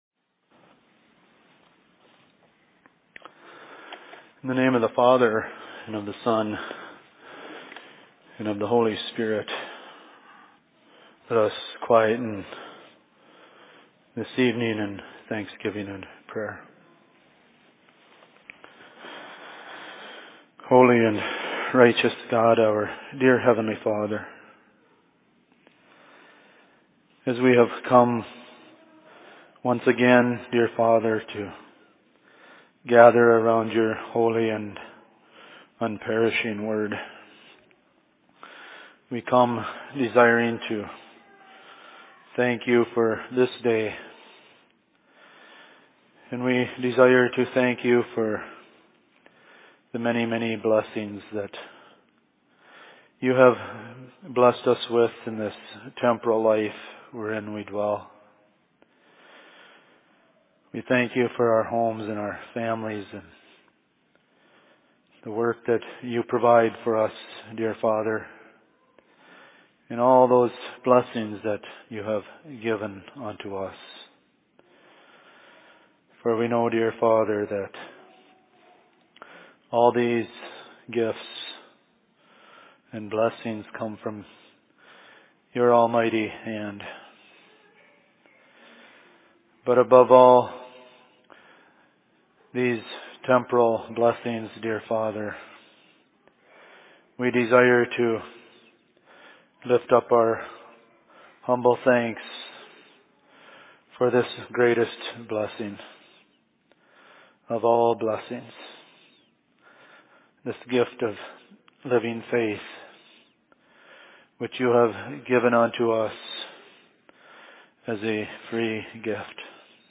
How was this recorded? Location: LLC Cokato